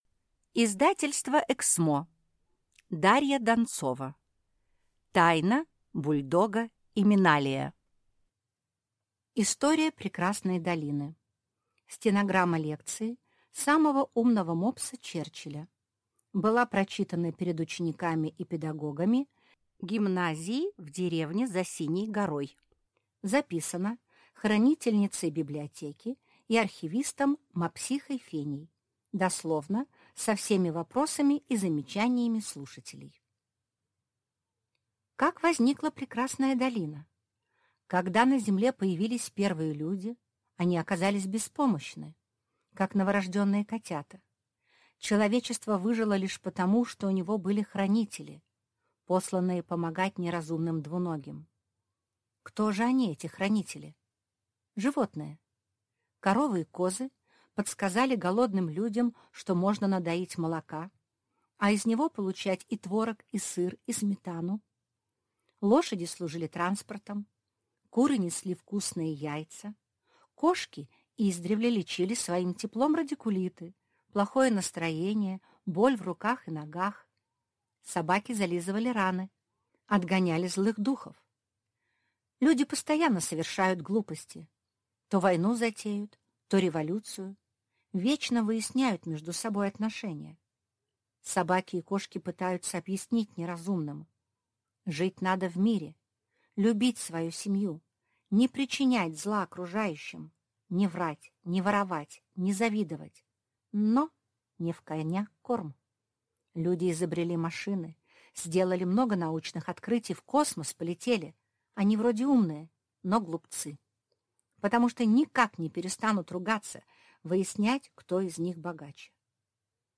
Аудиокнига Тайна бульдога Именалия | Библиотека аудиокниг